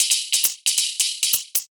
Index of /musicradar/ultimate-hihat-samples/135bpm
UHH_ElectroHatB_135-03.wav